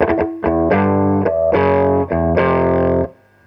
RIFF2.wav